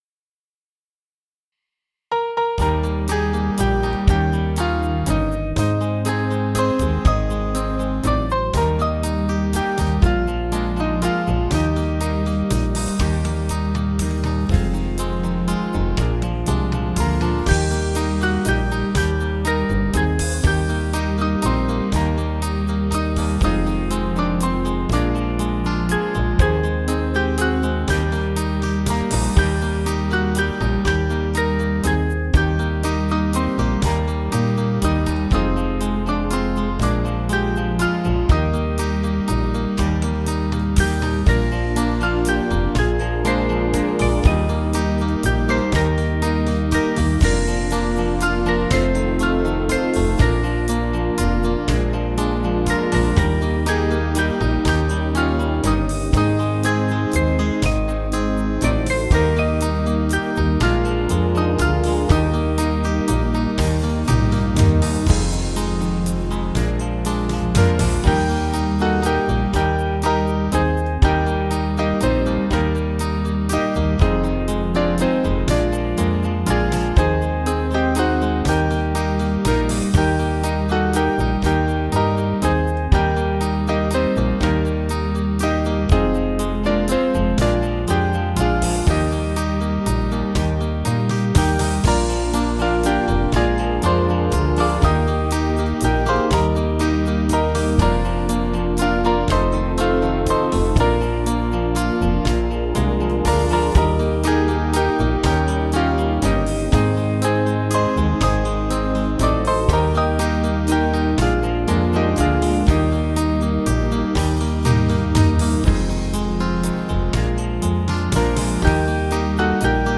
Small Band
(CM)   3/Eb